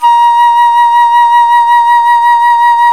Index of /90_sSampleCDs/Roland LCDP04 Orchestral Winds/FLT_Alto Flute/FLT_A.Flt vib 2
FLT ALTOFL0N.wav